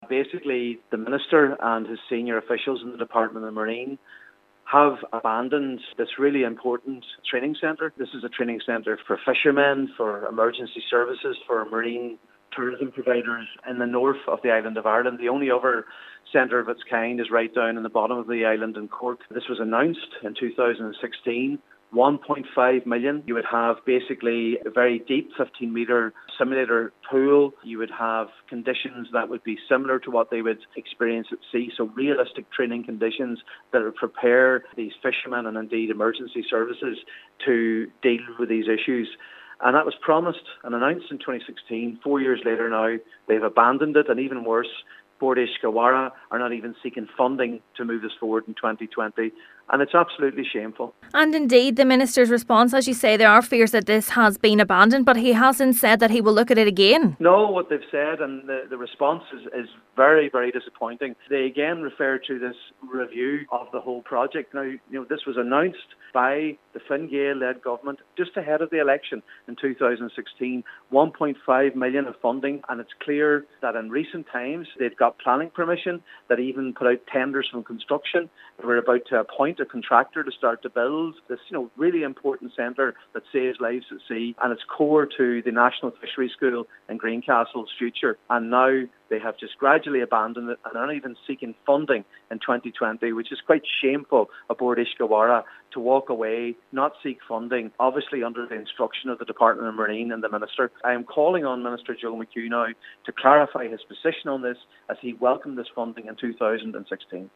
Senator MacLochlainn says it is a shameful decision: